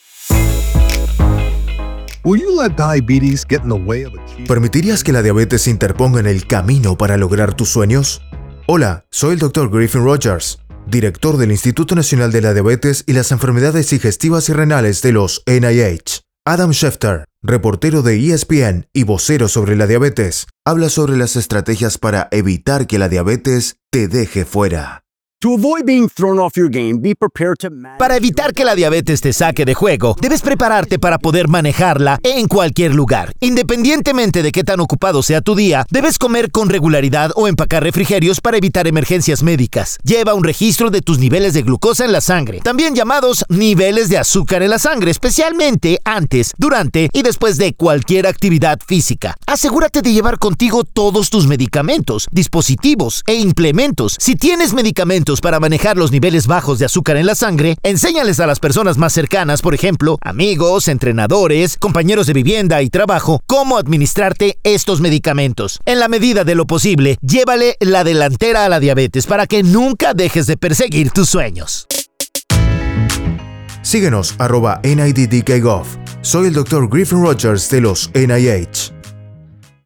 Transmisión radial Momentos saludables - NIDDK
Siga los enlaces con recomendaciones de un minuto sobre cómo llevar un estilo de vida saludable presentadas por el Dr. Griffin Rodgers, director del NIDDK.